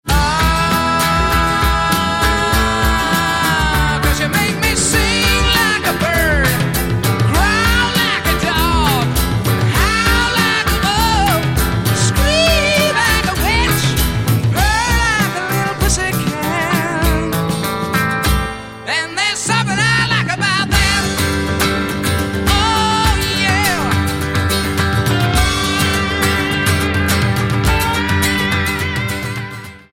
guitar, synthesizer, vocals
drums, percussion
guitar, keyboards, vocals
Album Notes: Recorded at Can-Base Studios, Vancouver, Canada